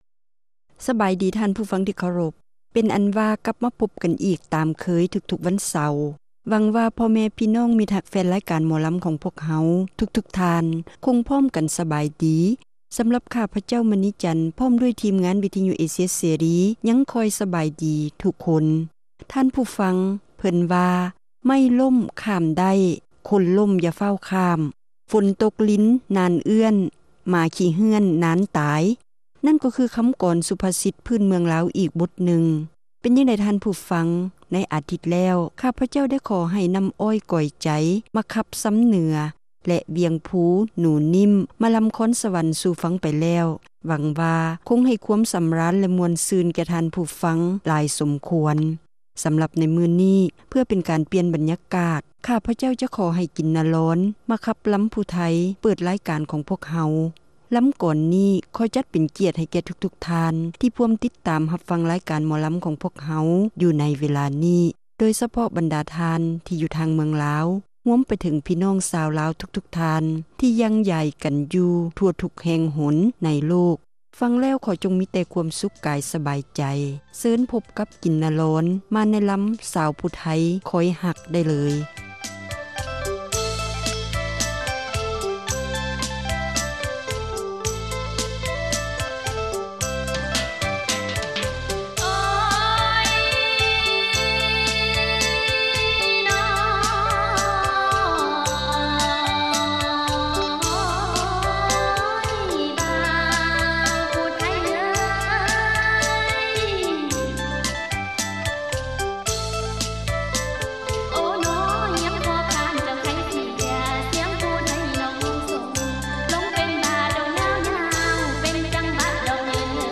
ຣາຍການໜໍລຳ ປະຈຳສັປະດາ ວັນທີ 14 ເດືອນ ທັນວາ ປີ 2007